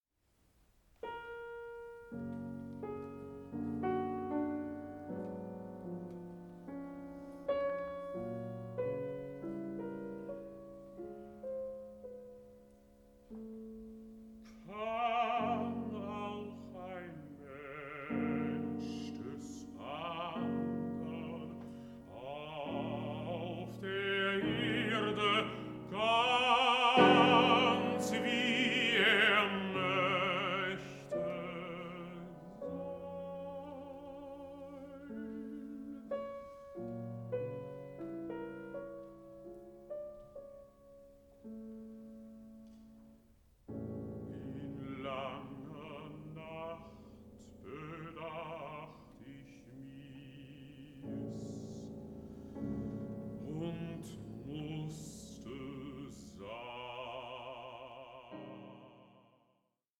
Songs to poems